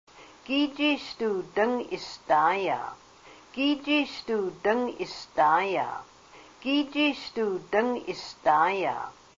The following are a collection of phrases recorded with native speakers of Haida during a trip to Ketchikan and Hydaburg, Alaska, in March, 2003.
a native speaker of the Kasaan dialect of Alaskan Haida.